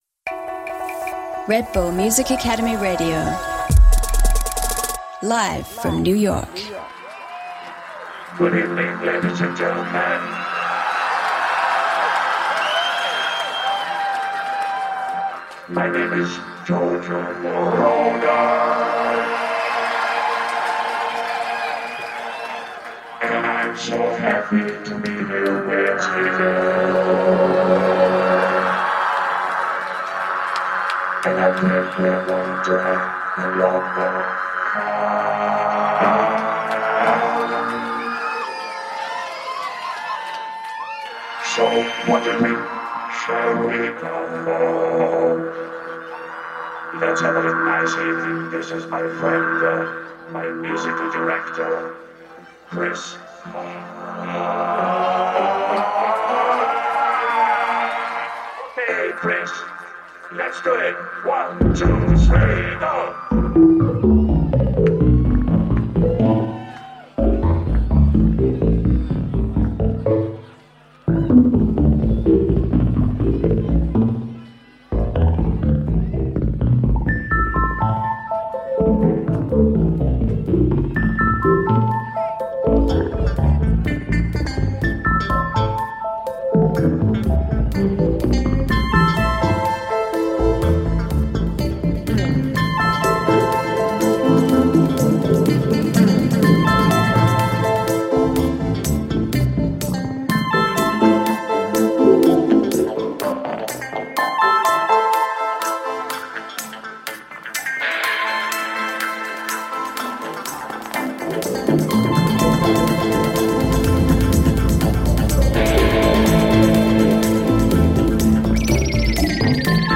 Жанр: Disco